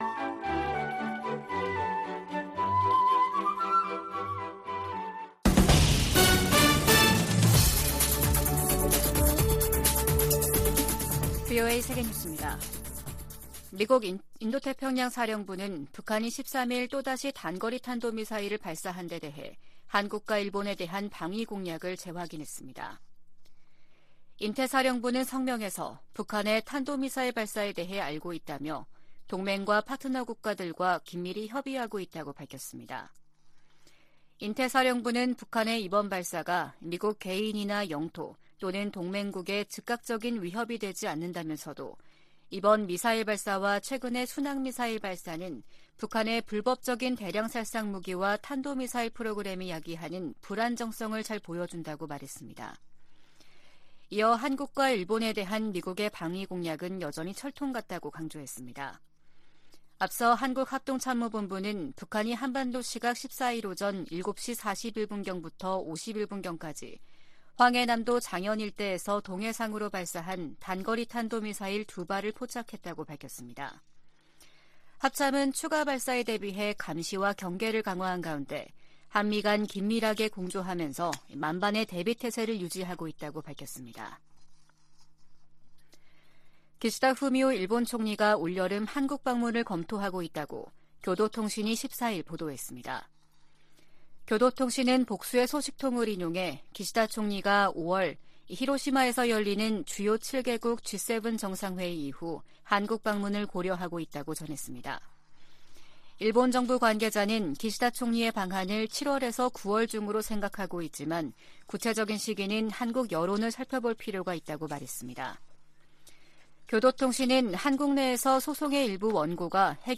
VOA 한국어 아침 뉴스 프로그램 '워싱턴 뉴스 광장' 2023년 3월 15일 방송입니다. 미국과 한국이 ‘자유의 방패’ 연합훈련을 실시하고 있는 가운데 북한은 미사일 도발을 이어가고 있습니다. 백악관은 한반도 안정을 저해하는 북한의 어떤 행동도 용납하지 않을 것이라고 경고했습니다. 한국을 주요 7개국(G7)에 포함시키는 방안을 추진해야 한다는 제안이 나온 데 전직 주한 미국대사들은 환영의 입장을 나타냈습니다.